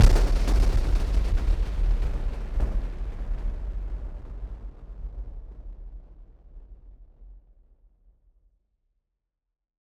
BF_SynthBomb_A-06.wav